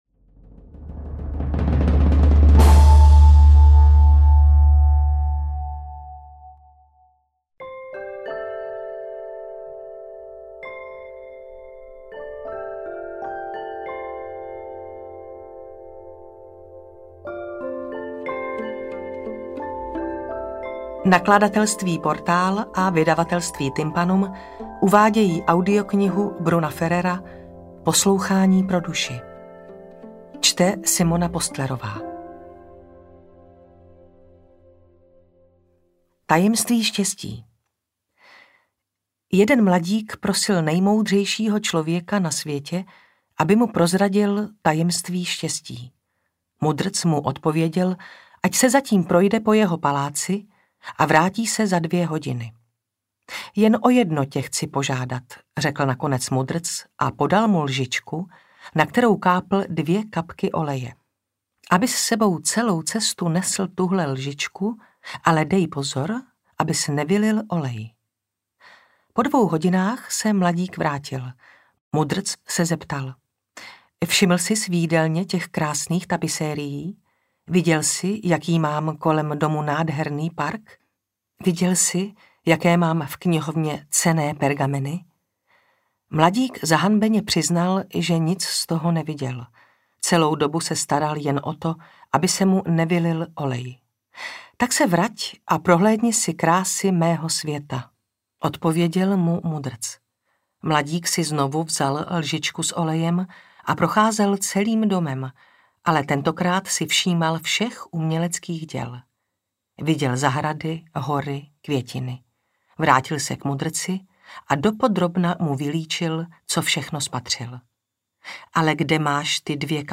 Poslouchání pro duši audiokniha
Ukázka z knihy